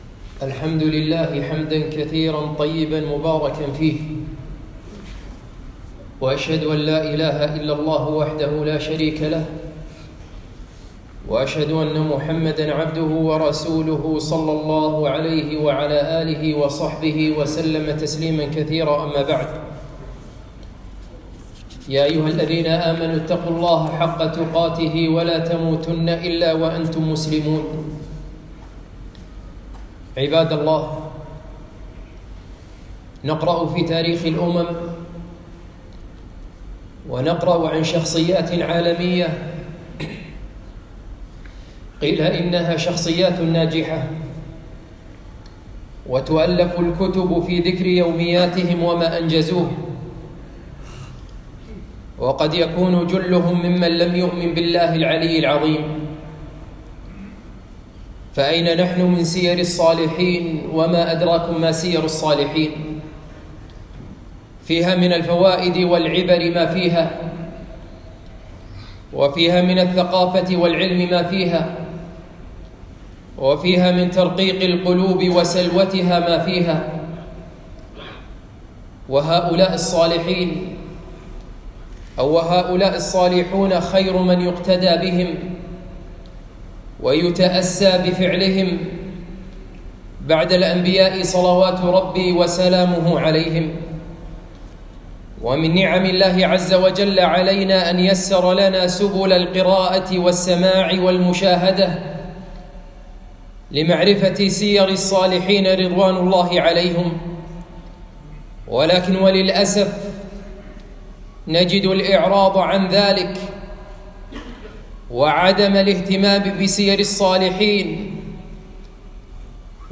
خطبة - أمهات المؤمنين رضي الله عنهن